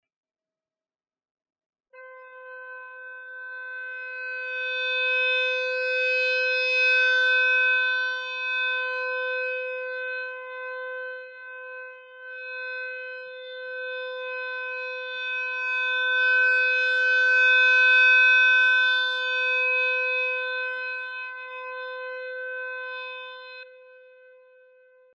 附两个长音的示范：